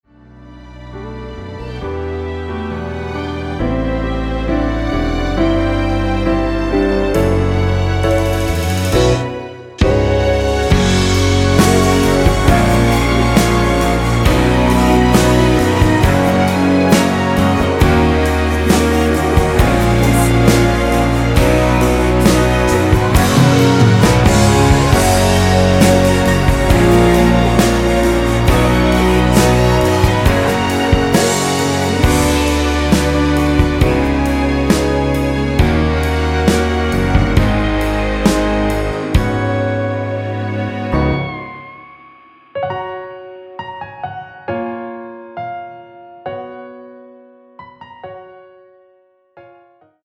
이곡의 코러스는 미리듣기에 나오는 부분밖에 없으니 참고 하시면 되겠습니다.
원키에서(+2)올린 코러스 포함된 MR입니다.(미리듣기 확인)
앞부분30초, 뒷부분30초씩 편집해서 올려 드리고 있습니다.
중간에 음이 끈어지고 다시 나오는 이유는